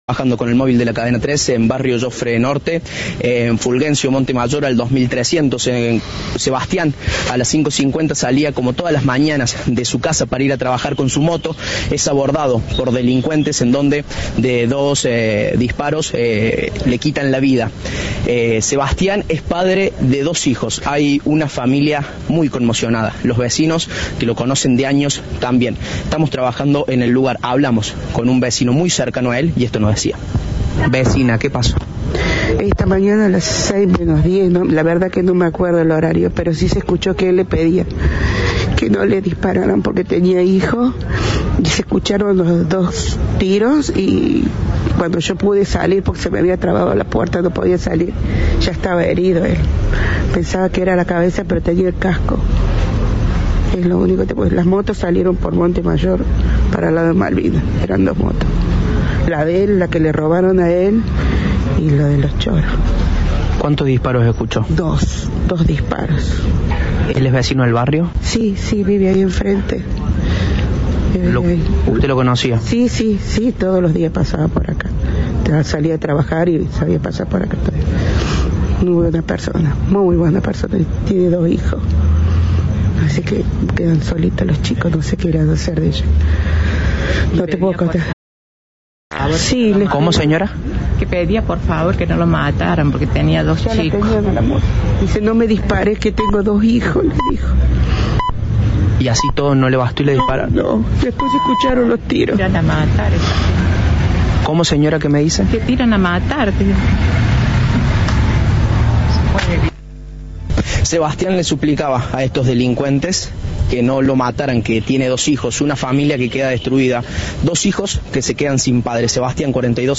Fue minutos antes de las 6 de este jueves, según contó a Cadena 3 una de las vecinas de esa barriada que no sale de la conmoción.
Informe